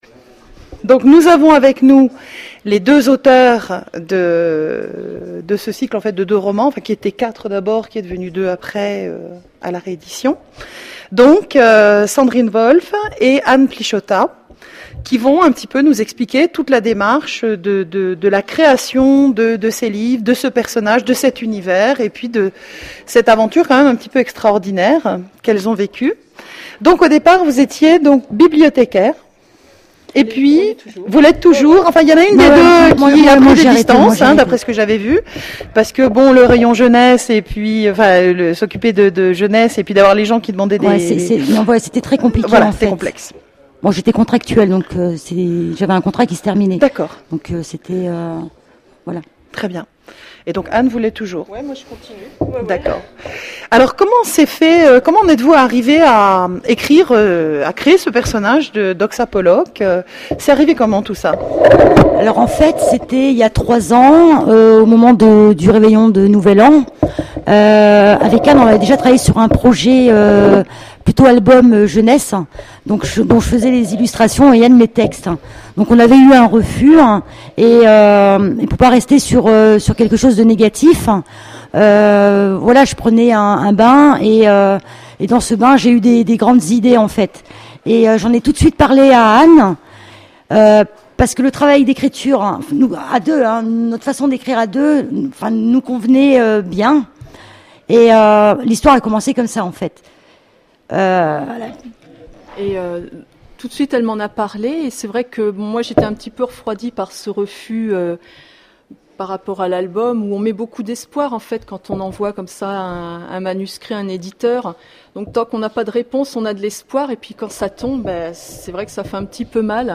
Conférence Imaginales 2010 : De la diffusion restreinte au lancement grand public… L’aventure Oksa Pollock
Mots-clés Edition Rencontre avec un auteur Conférence Partager cet article